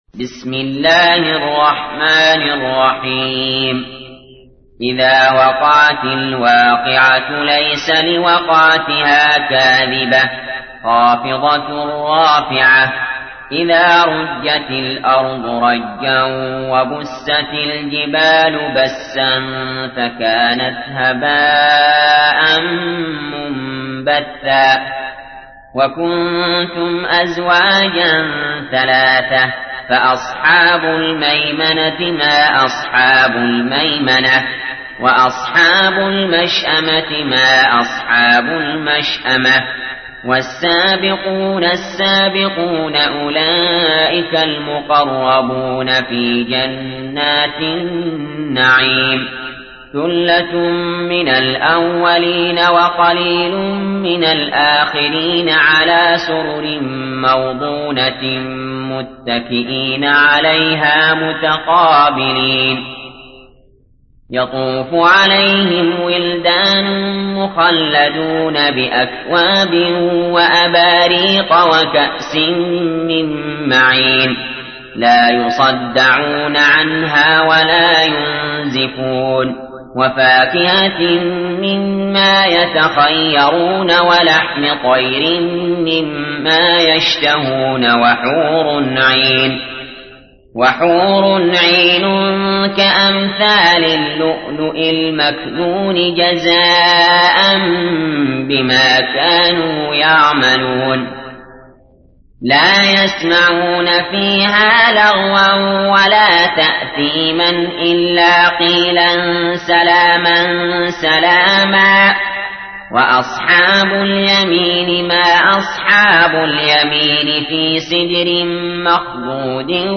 تحميل : 56. سورة الواقعة / القارئ علي جابر / القرآن الكريم / موقع يا حسين